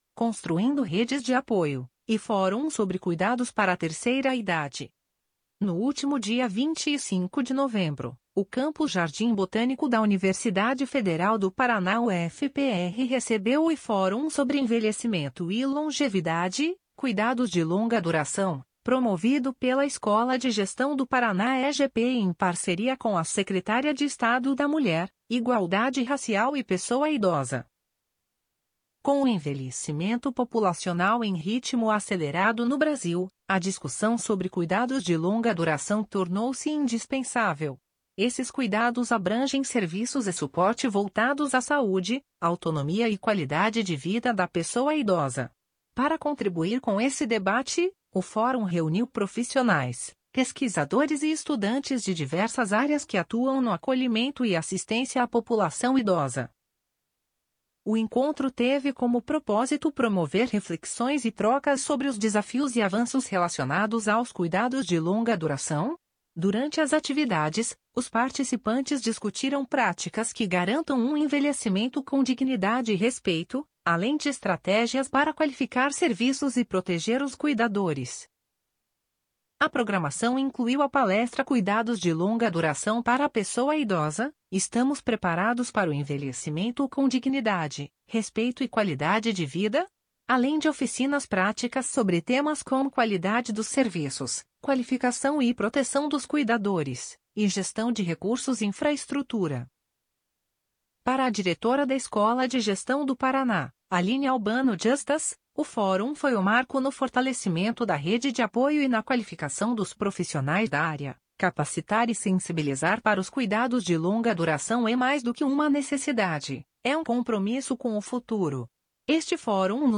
audionoticia_construindo_redes_de_apoio.mp3